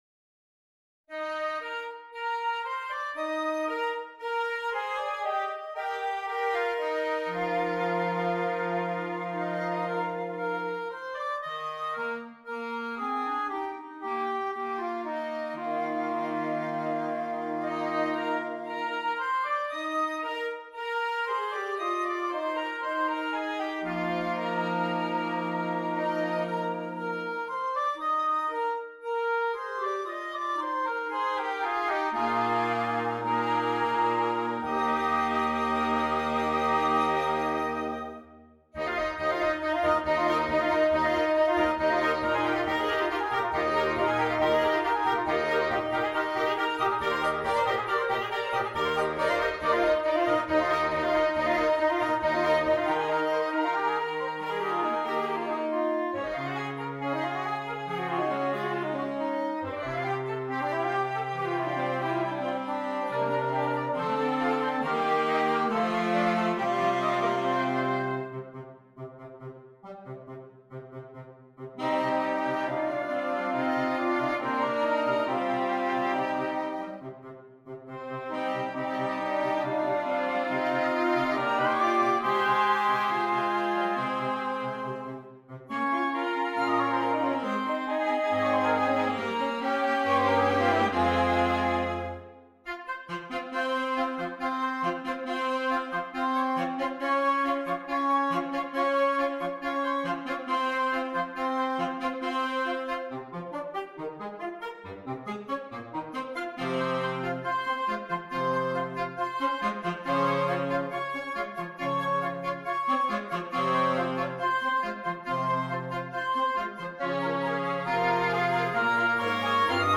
Voicing: Flexible Woodwind Quintet